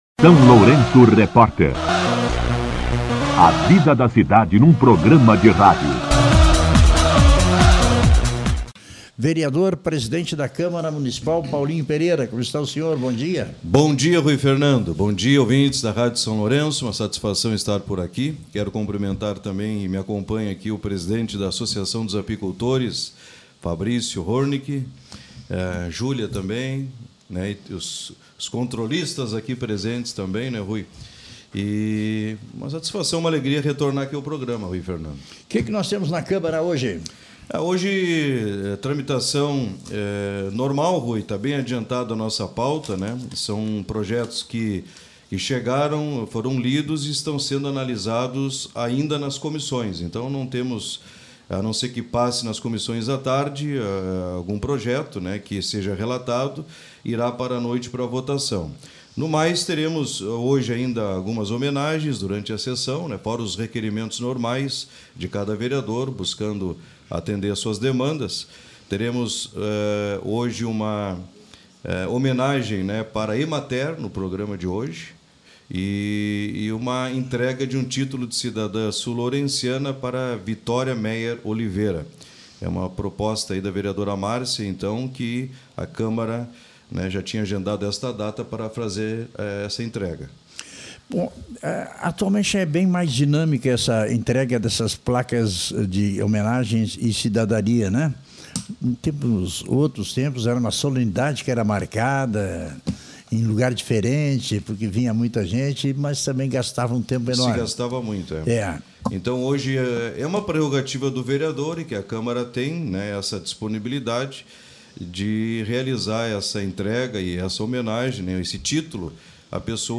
Entrevista com O presidente do Legislativo, Paulinho Pereira
O presidente do Legislativo, Paulinho Pereira (Podemos), concedeu entrevista ao SLR RÁDIO na manhã desta segunda-feira (13), abordando os projetos que atualmente tramitam nas comissões e que, em breve, deverão ser apreciados em plenário pela Câmara Municipal.